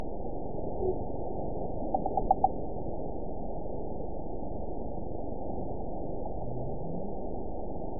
event 912477 date 03/27/22 time 11:05:52 GMT (3 years, 1 month ago) score 9.62 location TSS-AB05 detected by nrw target species NRW annotations +NRW Spectrogram: Frequency (kHz) vs. Time (s) audio not available .wav